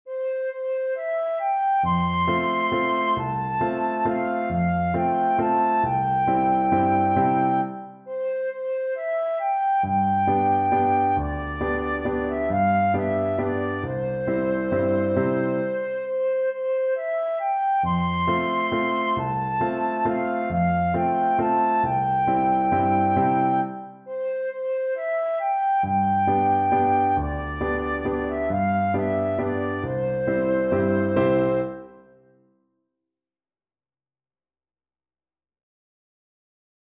One in a bar .=c.45
3/4 (View more 3/4 Music)
C6-C7
Flute  (View more Easy Flute Music)